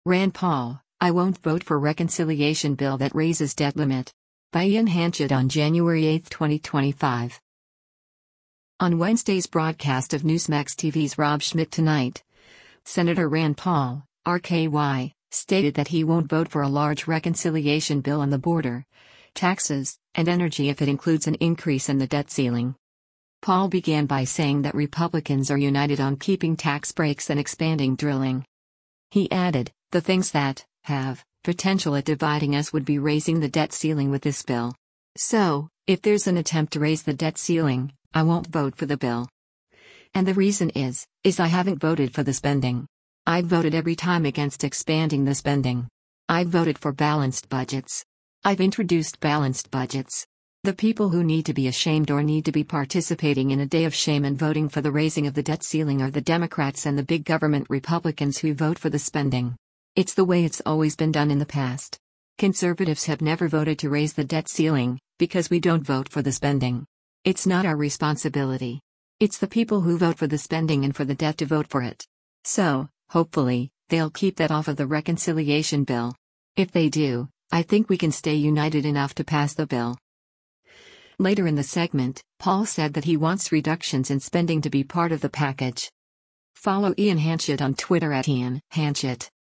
On Wednesday’s broadcast of Newsmax TV’s “Rob Schmitt Tonight,” Sen. Rand Paul (R-KY) stated that he won’t vote for a large reconciliation bill on the border, taxes, and energy if it includes an increase in the debt ceiling.